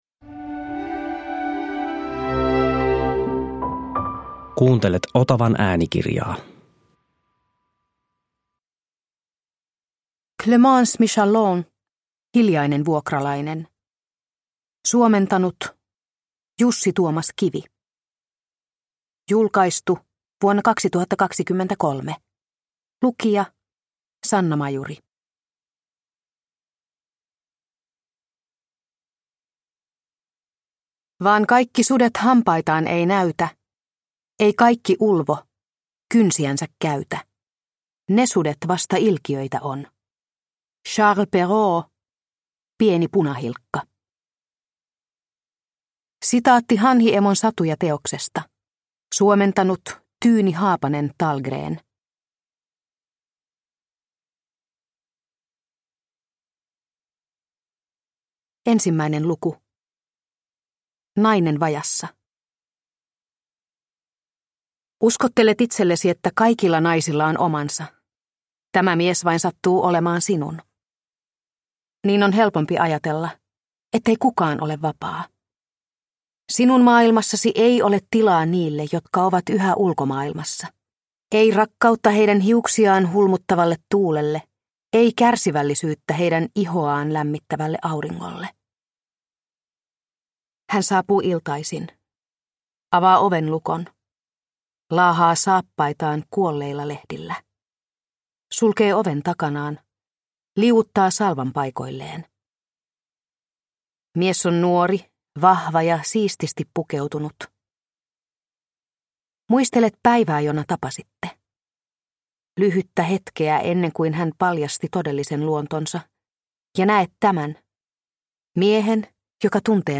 Hiljainen vuokralainen – Ljudbok – Laddas ner